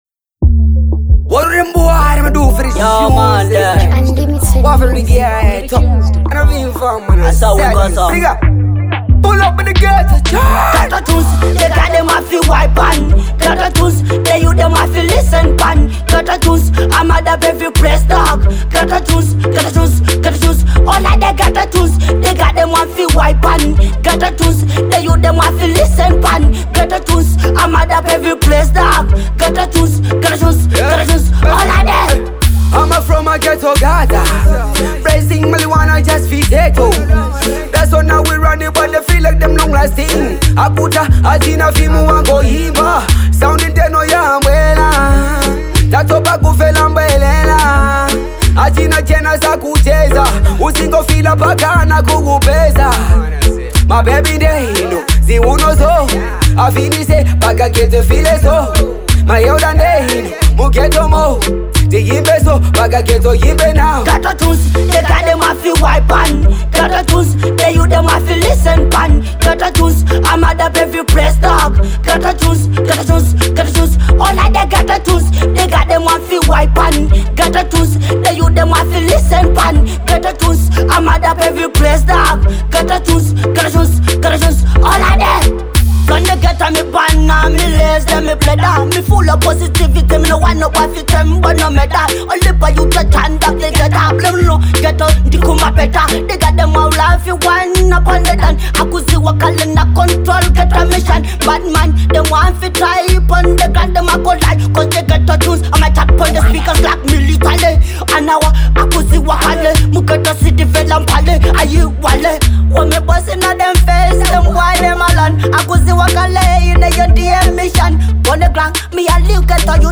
Dancehall 2023 Malawi